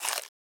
Crunch Bite Item (1).wav